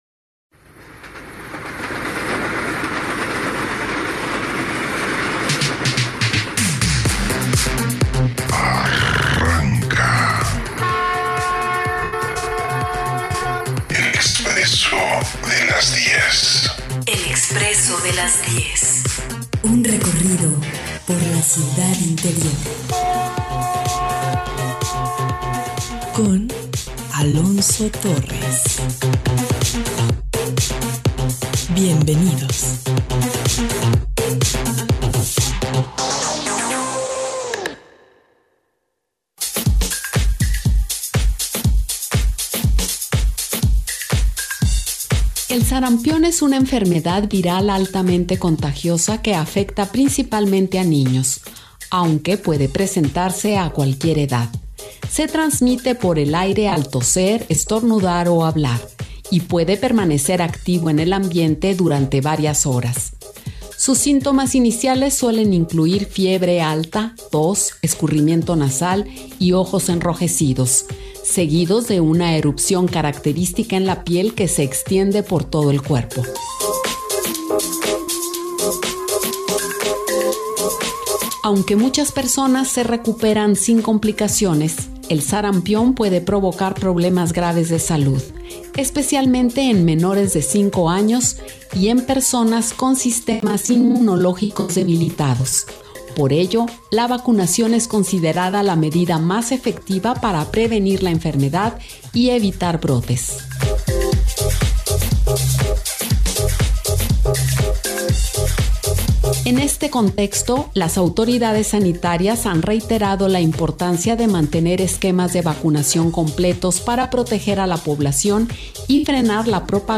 en vivo desde el Hospital General de Occidente "Zoquipan"